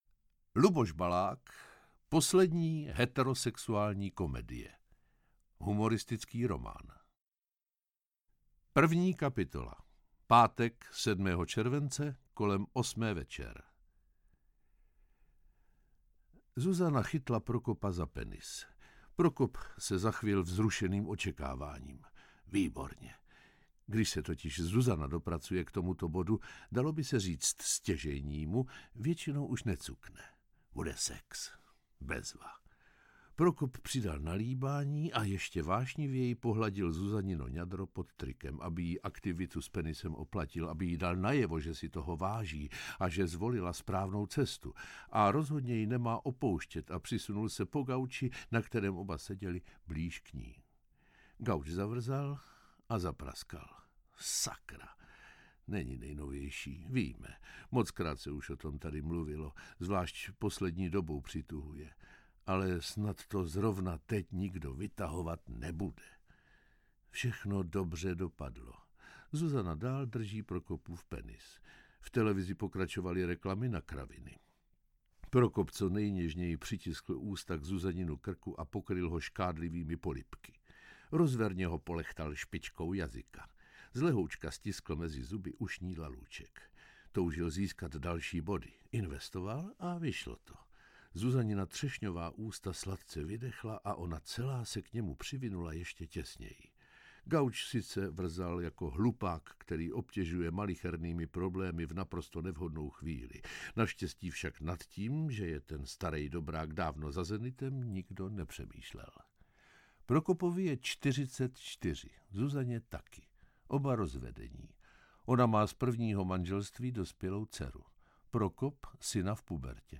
Audioknihu namluvil Miroslav Donutil.